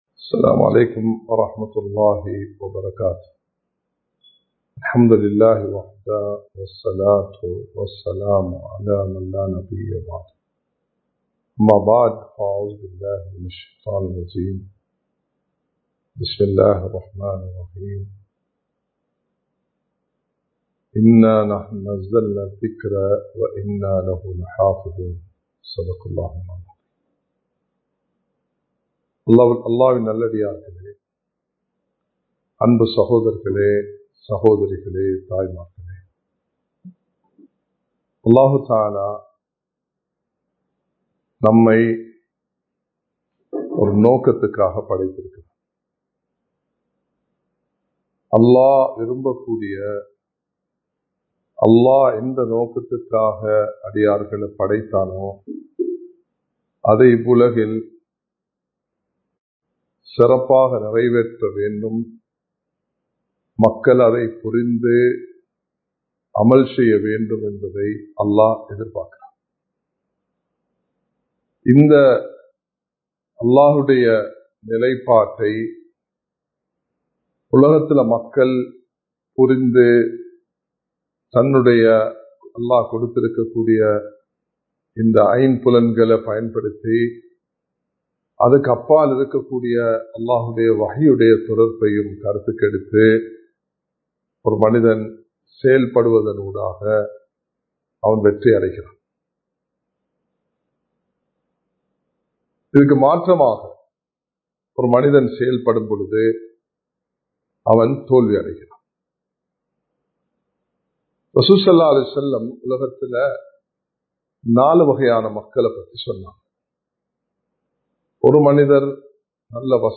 இரண்டு வகையான சோதனைகள் | Audio Bayans | All Ceylon Muslim Youth Community | Addalaichenai
Live Stream